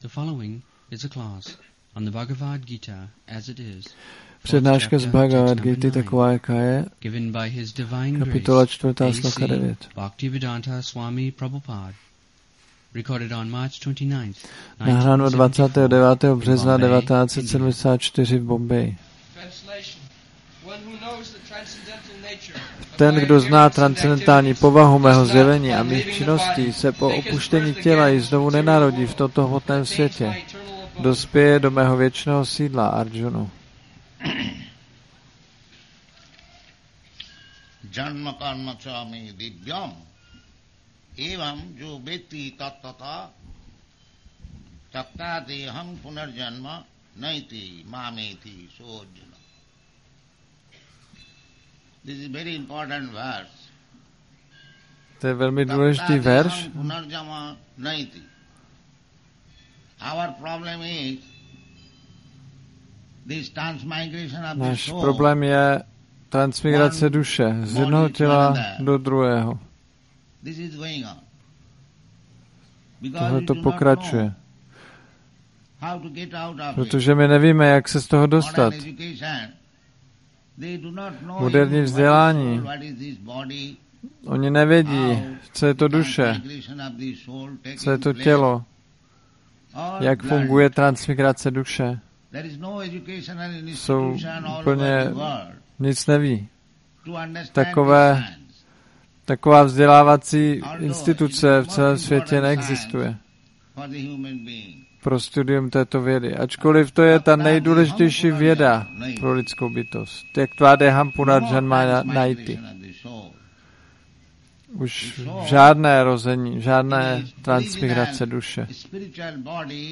1974-03-29-ACPP Šríla Prabhupáda – Přednáška BG-4.9 Bombay